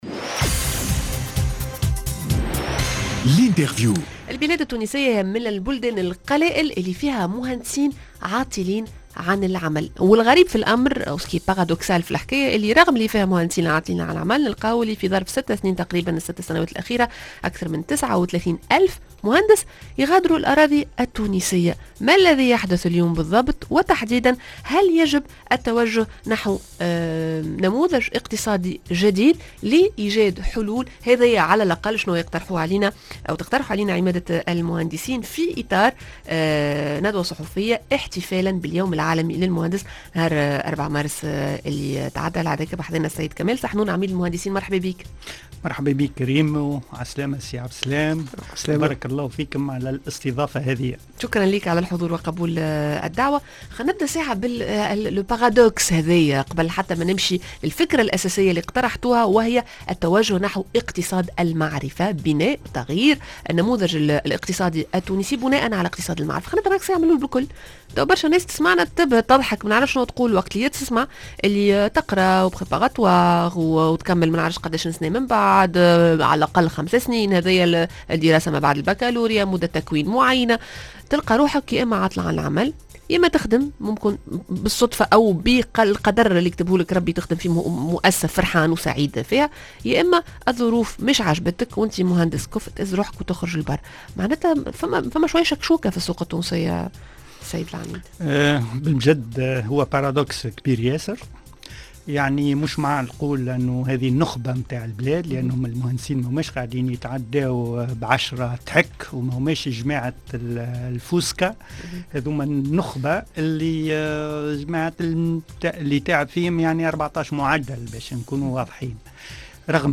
Interview Eco Mag